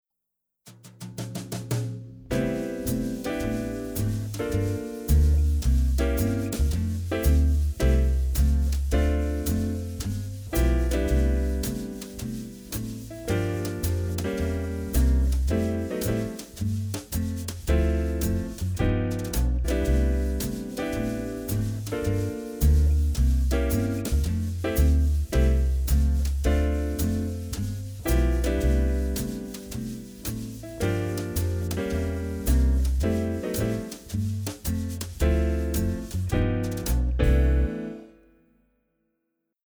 Akkordprogression med modulation og gehørsimprovisation:
Lyt efter bassen, der ofte spiller grundtonen.
Modulation til 4.trin
C instrument (demo)